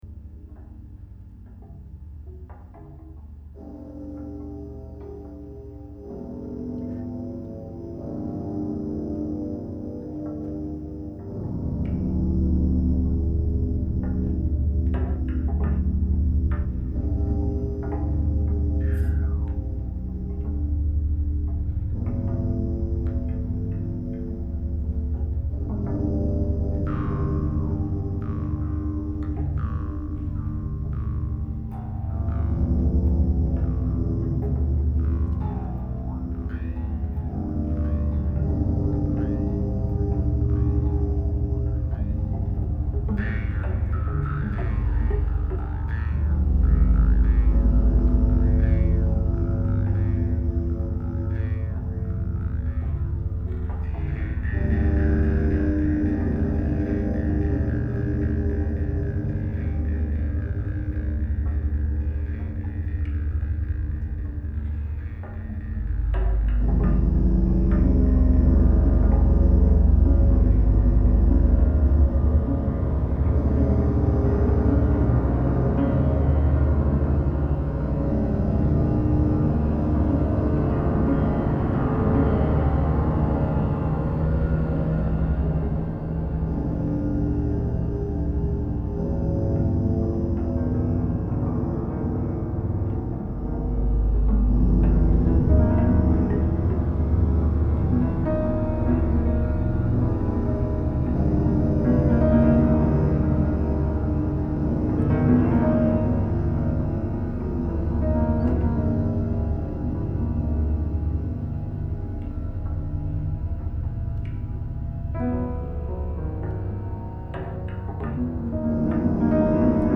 Le 12 février 2017 à La Garenne-Colombes